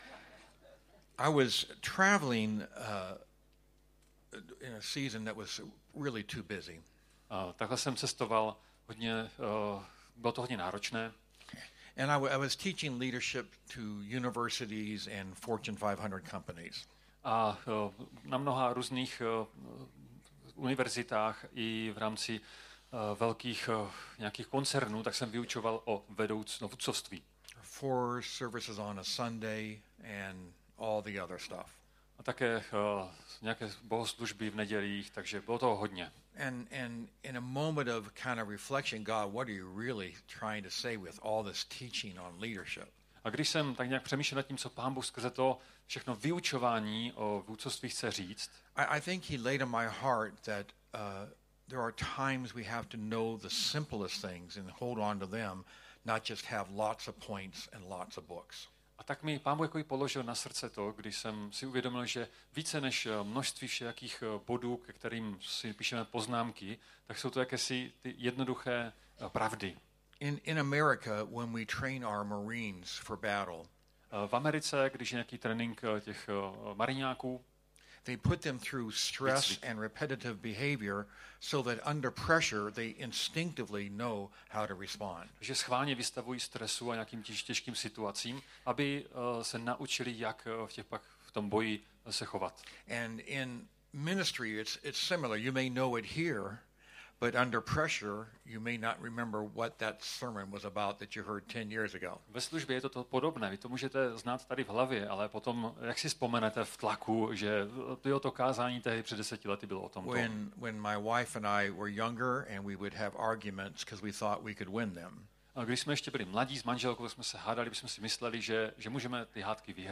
SEMINÁR 1